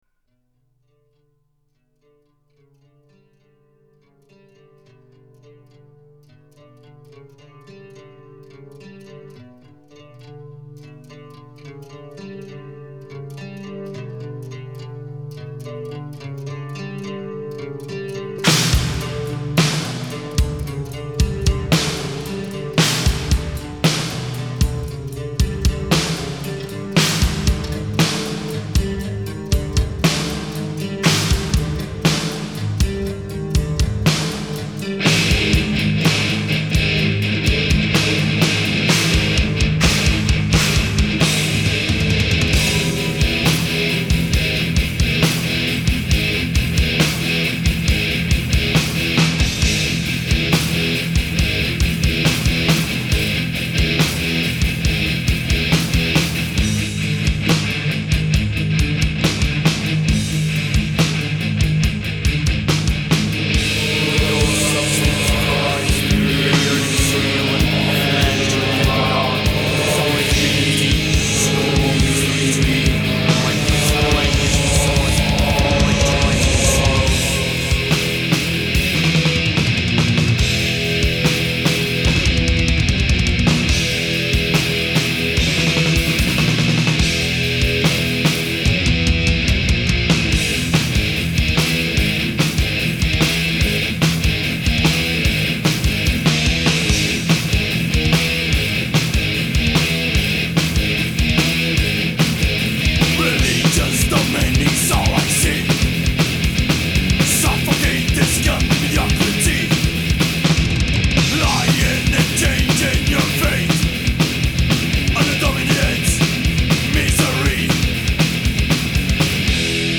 Жанр: Thrash, Metal